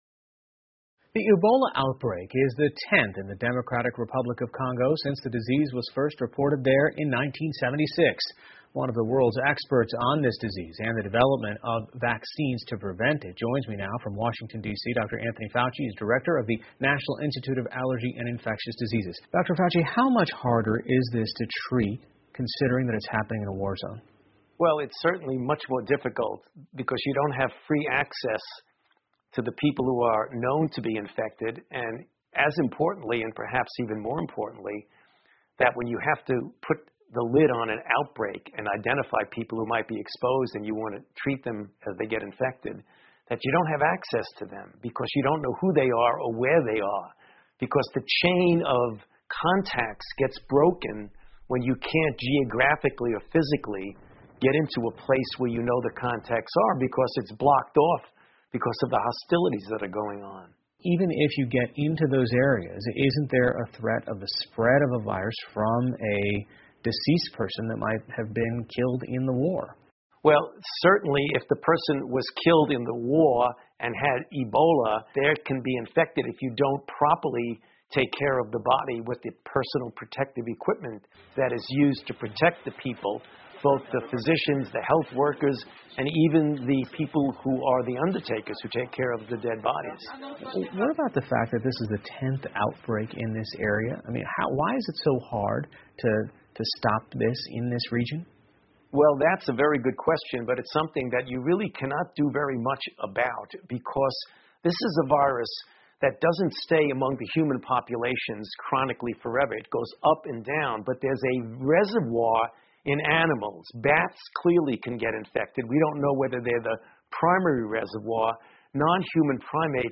PBS高端访谈:刚果启动接种埃博拉疫苗行动 听力文件下载—在线英语听力室